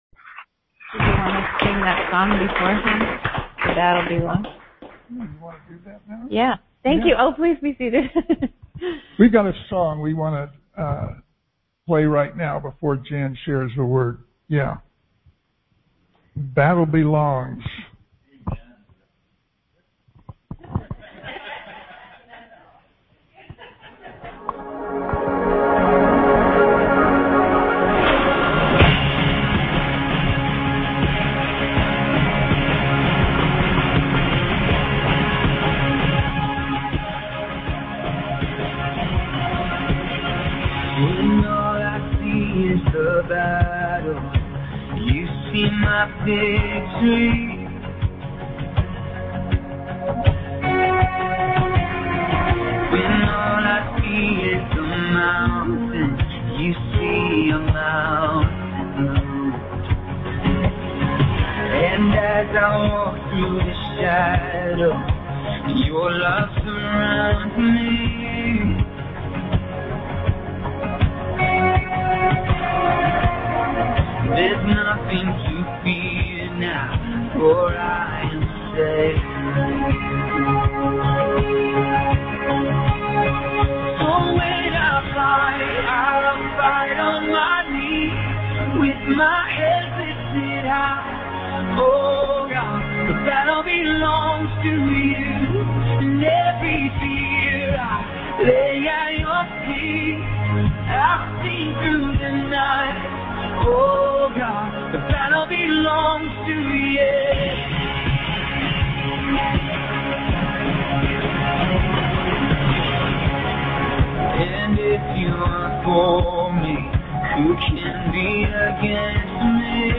Conference Call Fellowship